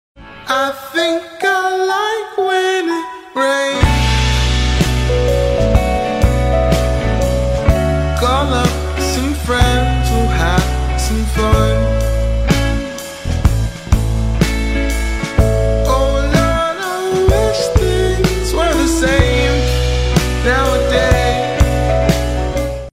Hawksbill turtle and the rhythmic sound effects free download
Hawksbill turtle and the rhythmic pattern of raindrops